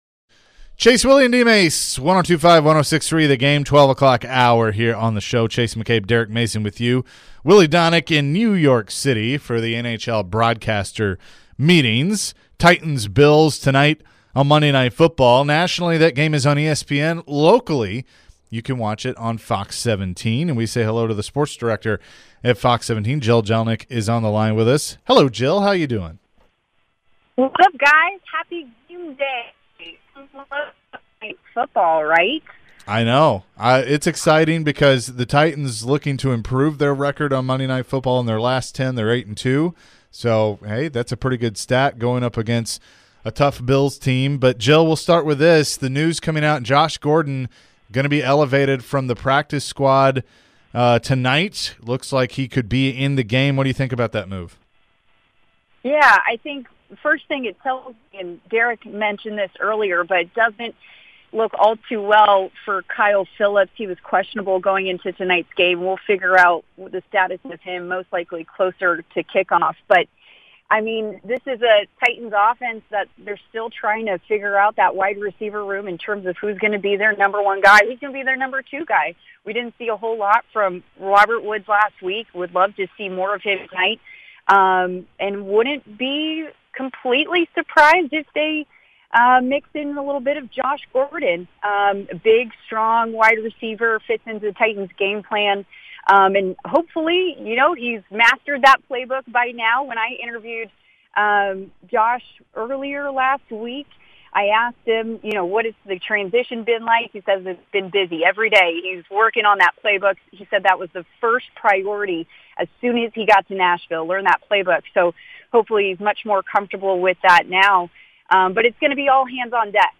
Predators forward Tanner Jeannot calls in before the Preds have their annual Smashville Showdown with the Vandy baseball team.